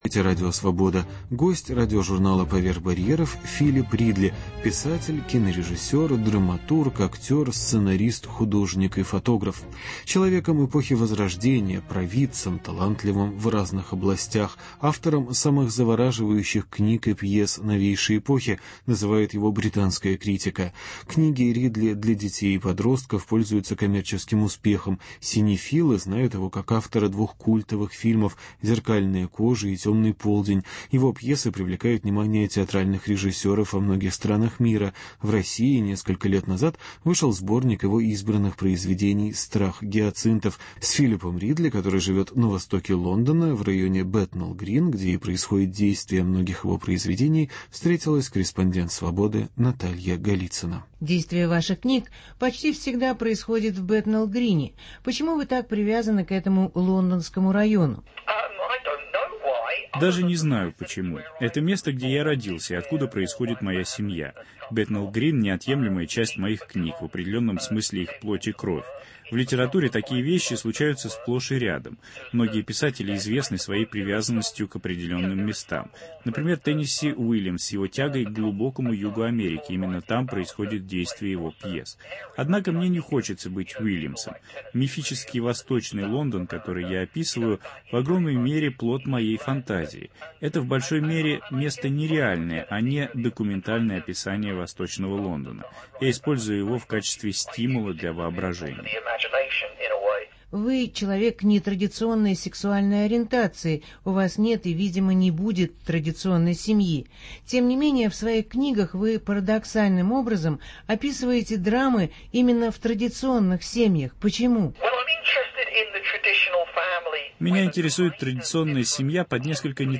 Интервью с британским кинорежиссером и драматургом Филипом Ридли.